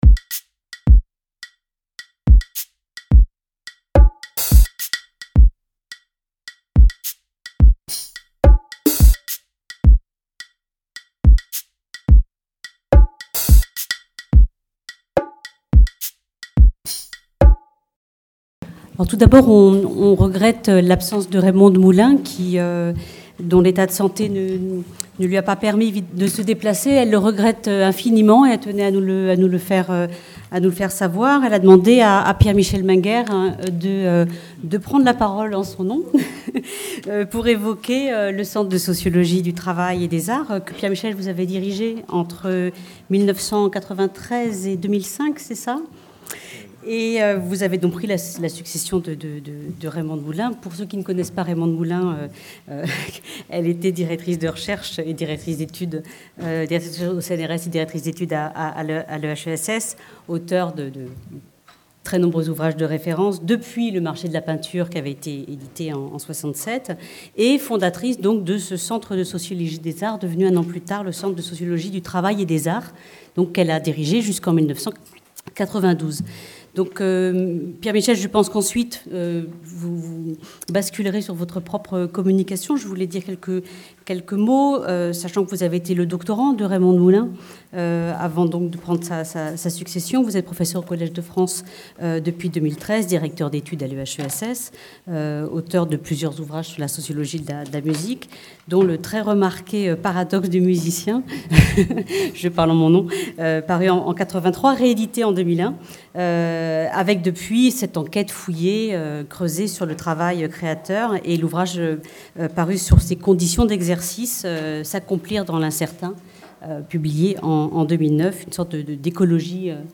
Colloque organisé à l’occasion du 40e anniversaire de l’EHESS